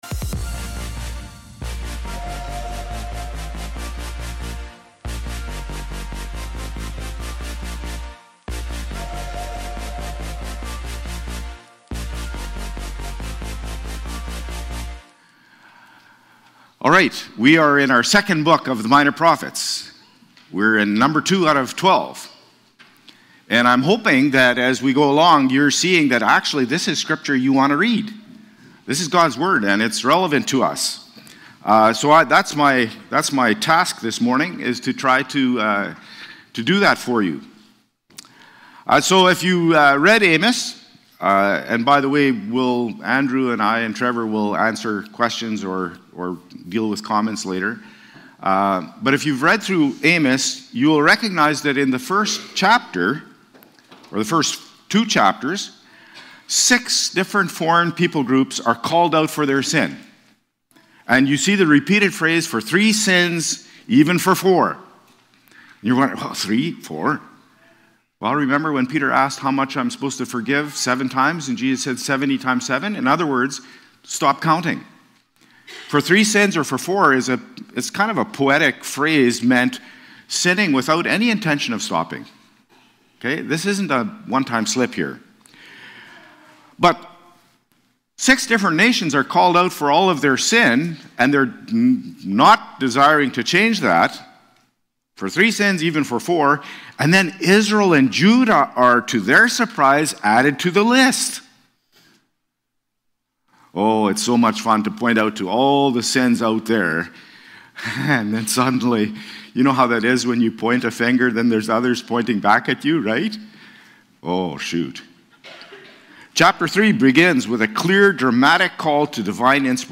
January-19-worship-service.mp3